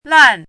chinese-voice - 汉字语音库
lan4.mp3